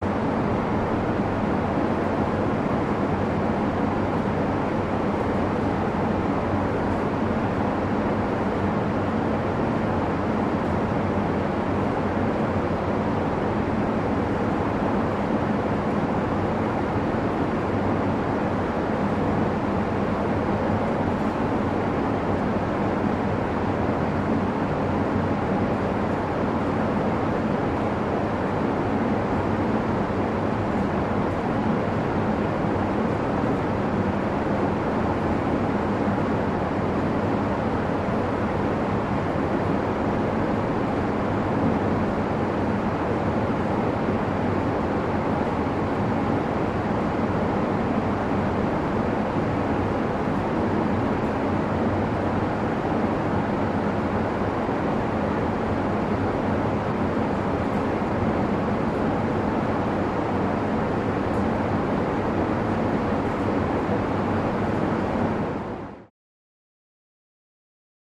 Room Ambience; Parking Garage Air Conditioner 1 No Traffic